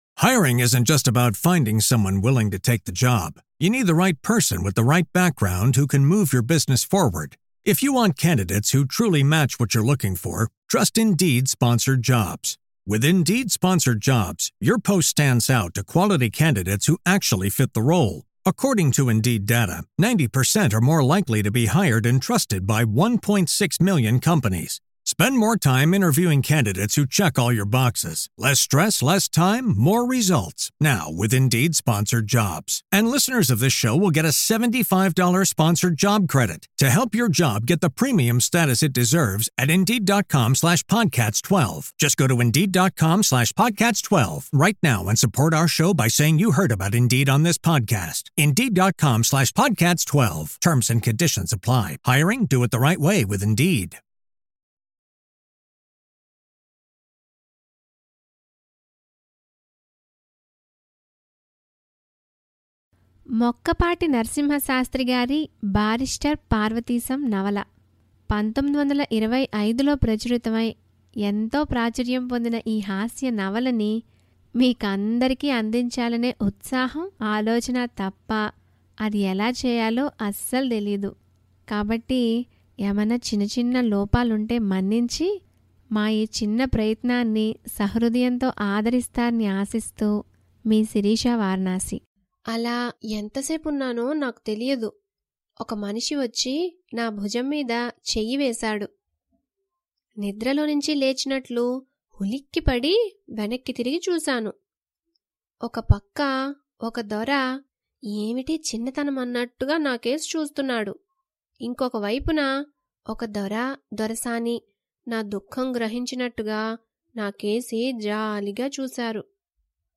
Barrister Parvateesam - Audio novel